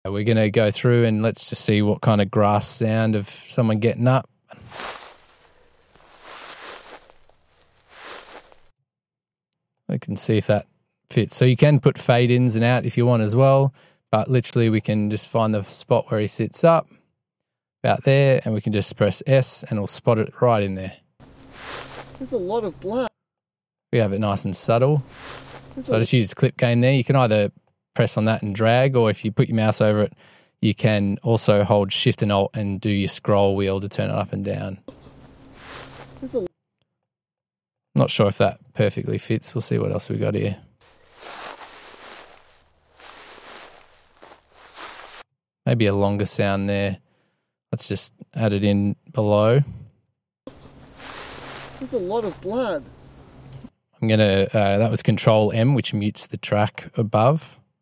Sound Design Tutorial.